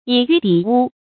以玉抵烏 注音： ㄧˇ ㄧㄩˋ ㄉㄧˇ ㄨ 讀音讀法： 意思解釋： 見「以玉抵鵲」。